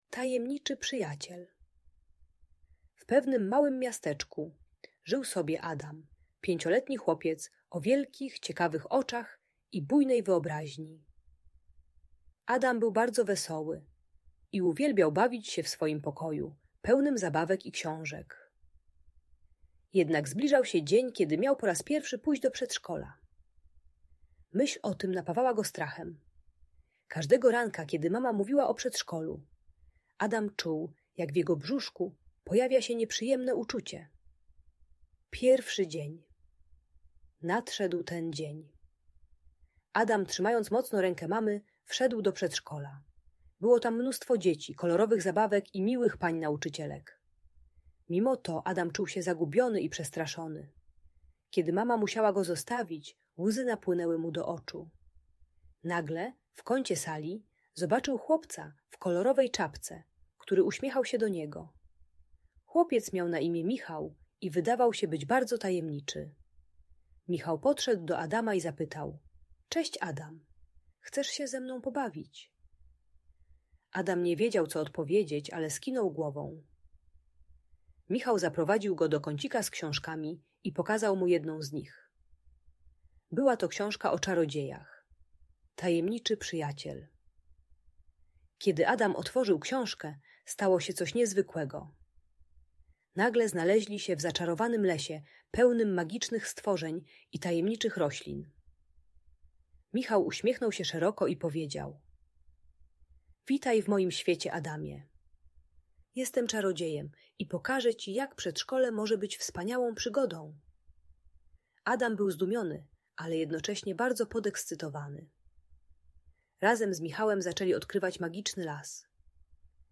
Tajemniczy Przyjaciel - historia o odwadze i przygodach - Audiobajka